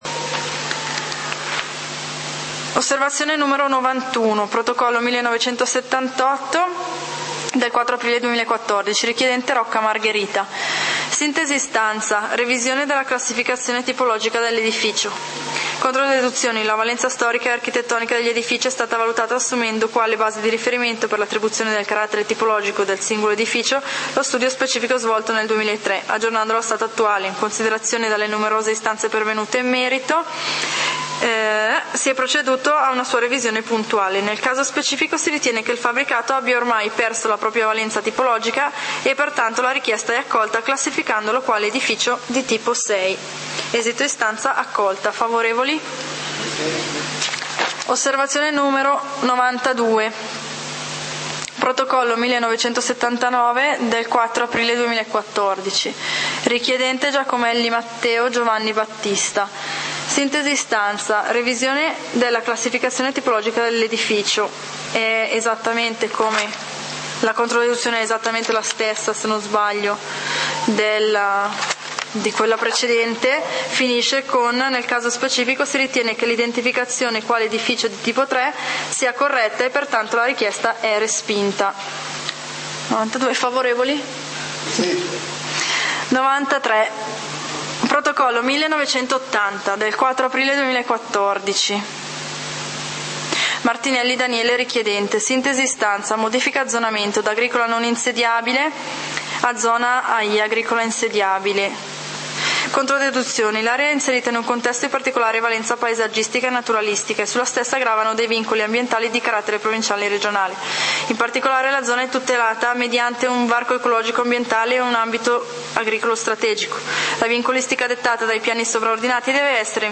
Consiglio comunale di Valdidentro del 30 Giugno 2014